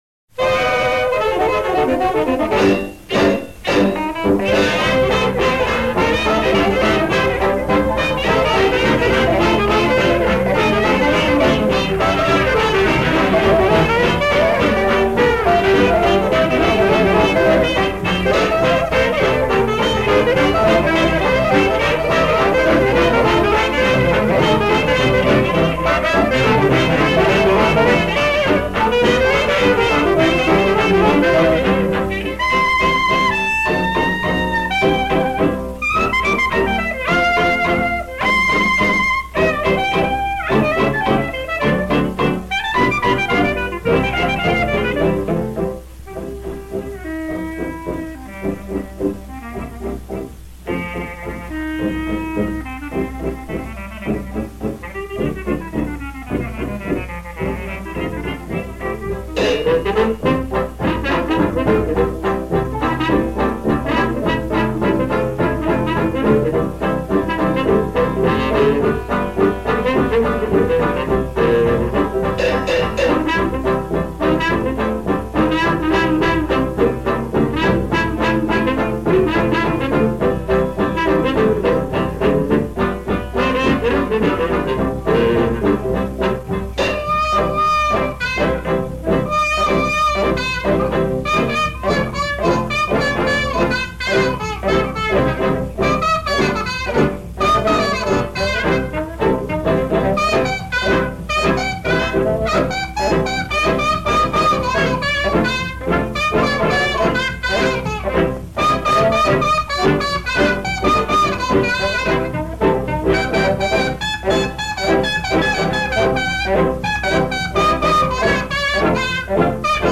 cornet